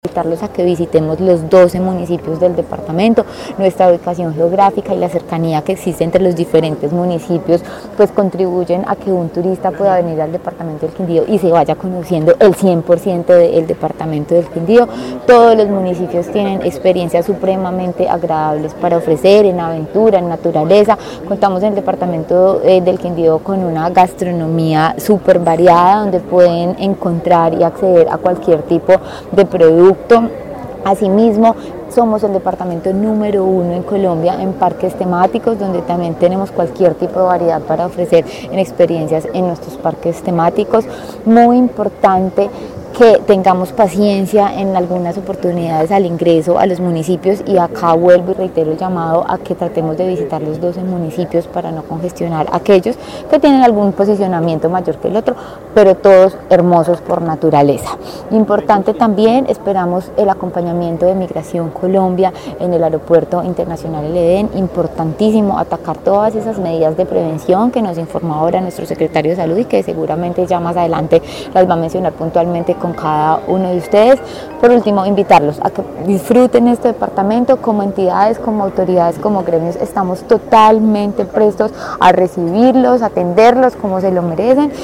Secretaria de Turismo del Quindío, Juana Gómez